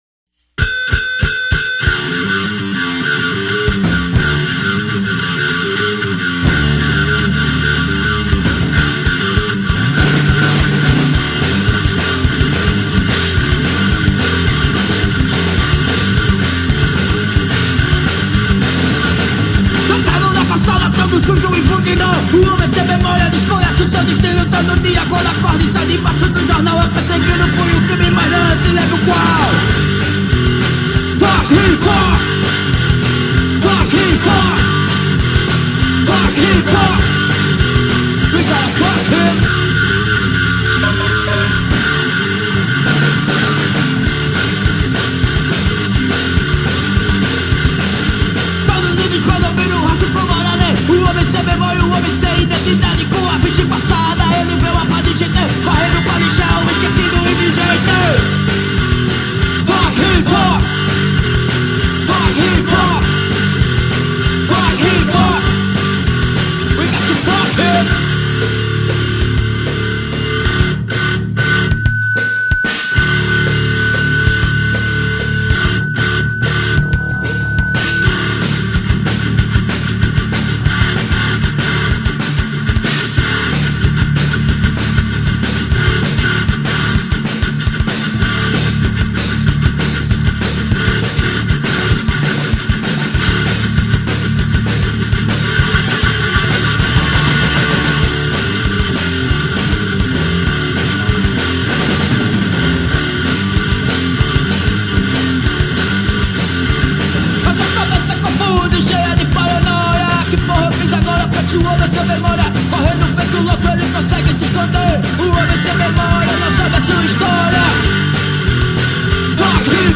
Som direto, sem excesso, com peso e intenção.